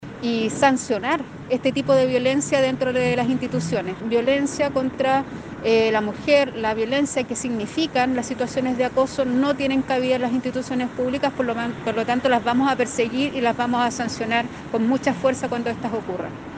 Es así como la alcaldesa de Quilpué, Valeria Melipillán, señaló que en su municipio actualmente existe un tipo de sanción menor, pero que ya se encuentran en reuniones para desarrollar un protocolo interno, en relación a las medidas de sanción y reparación para los trabajadoras afectadas por situaciones de acoso.